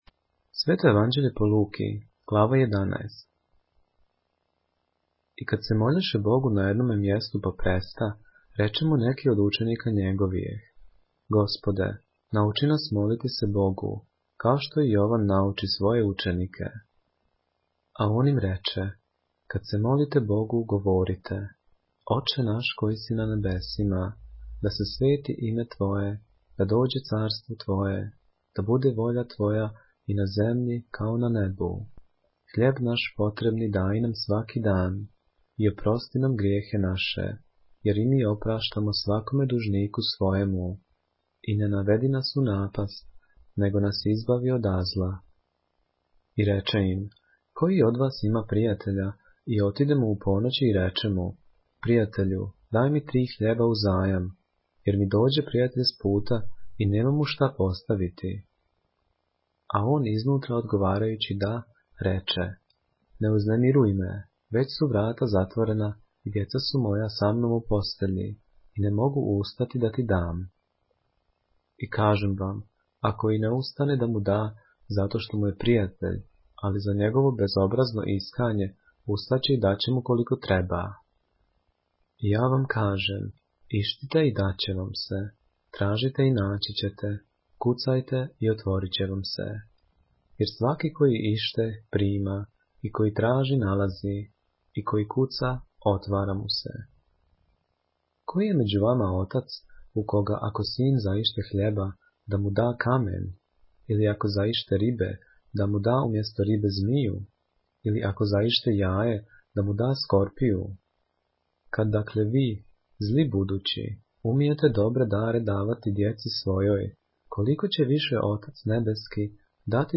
поглавље српске Библије - са аудио нарације - Luke, chapter 11 of the Holy Bible in the Serbian language